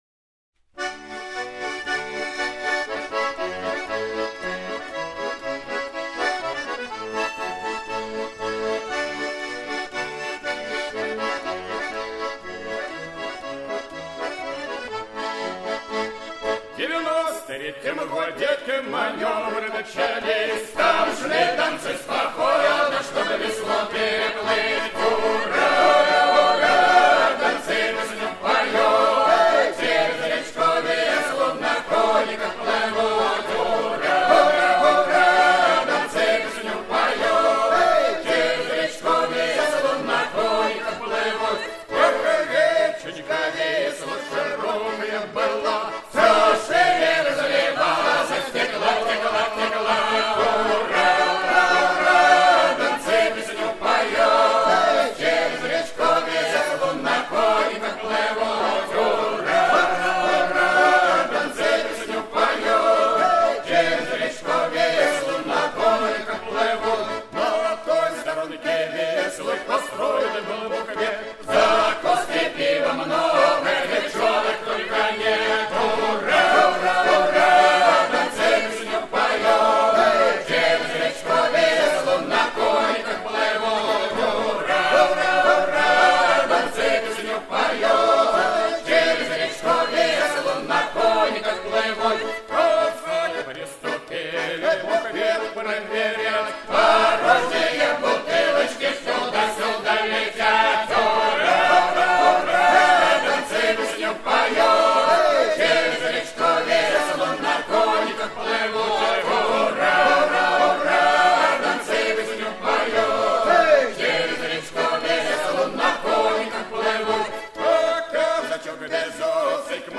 • Жанр: Детские песни
Ансамбль казачьей песни